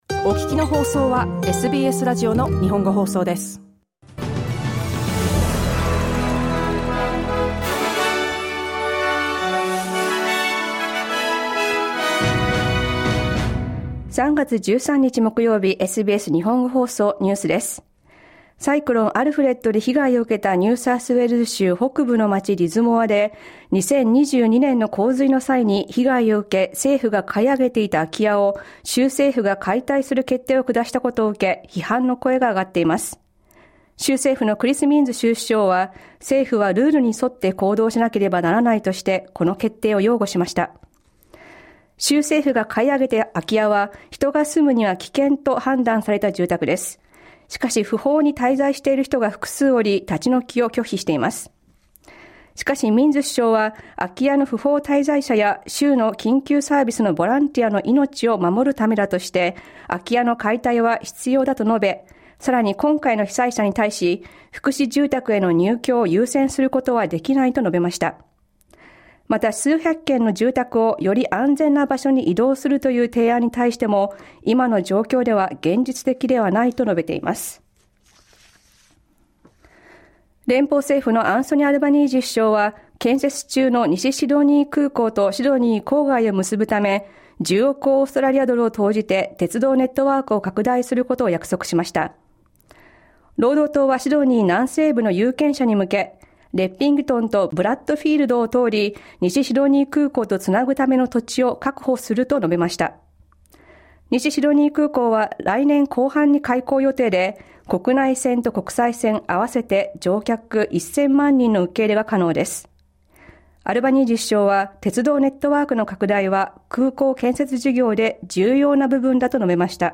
サイクロン「アルフレッド」の被害を受けたニューサウスウェールズ州リズモアで、2022年の洪水の際に被害を受け州政府が買い上げていた住宅の解体を決めたことで、批判の声が上がっています。午後１時から放送されたラジオ番組のニュース部分をお届けします。